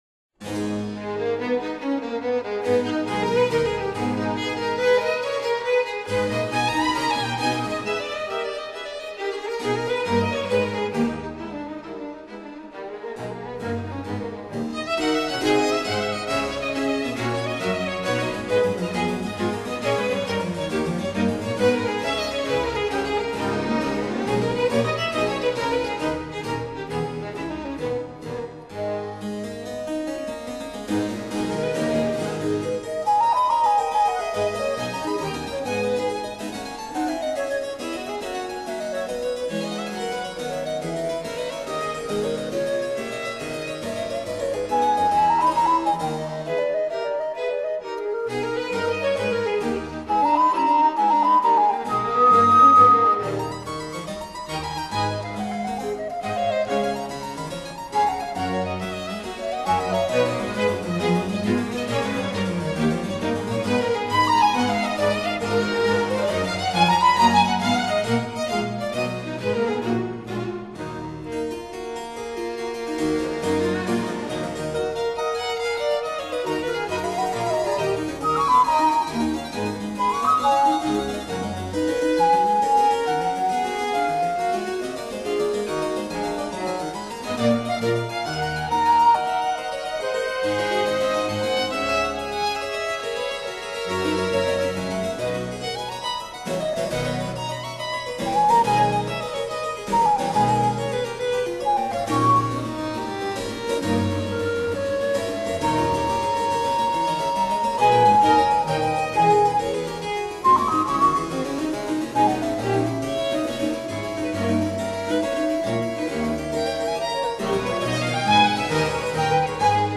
pour flae, violon, clavecin, cordes & bc